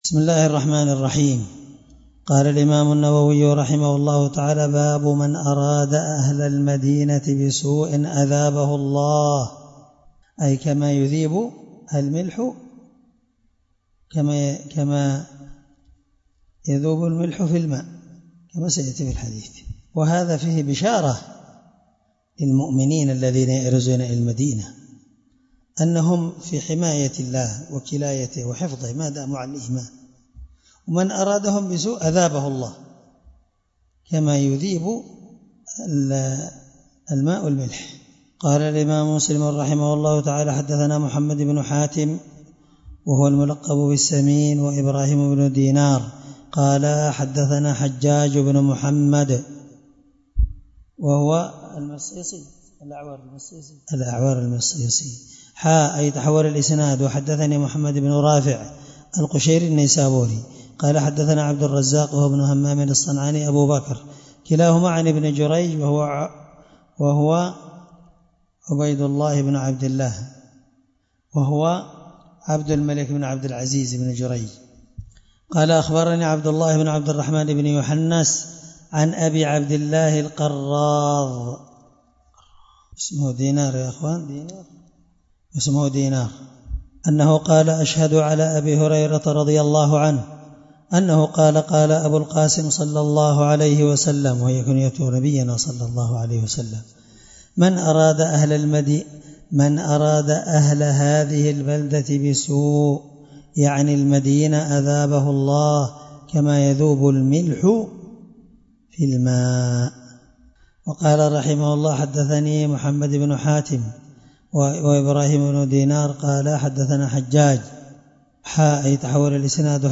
الدرس96من شرح كتاب الحج حديث رقم(1386-1387) من صحيح مسلم